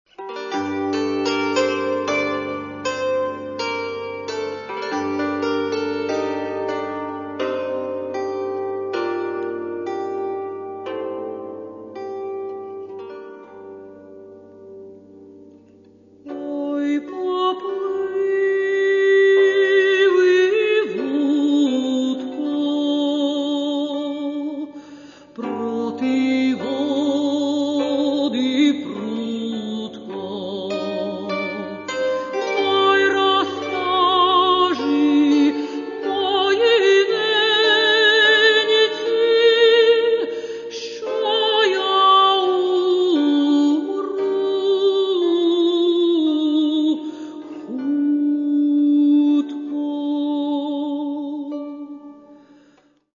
музика: народна пісня